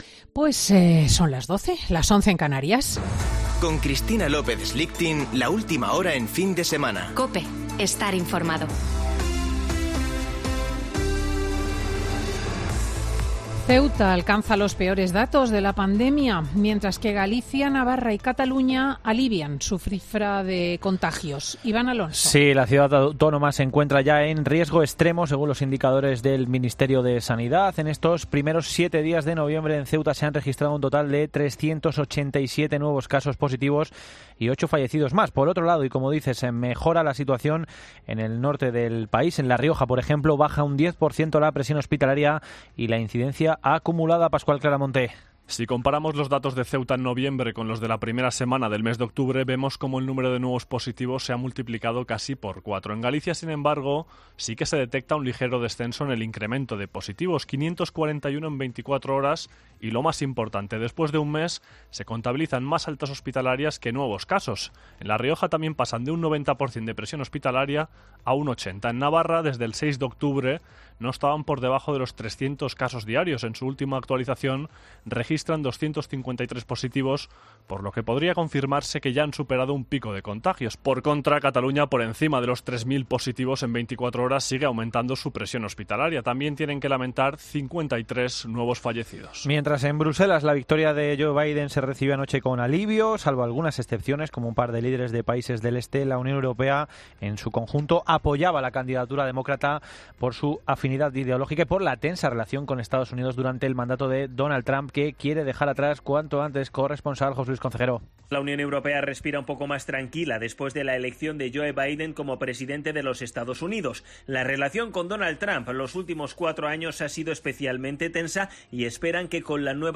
Boletín de noticias de COPE del 8 de noviembre de 2020 a las 12.00 horas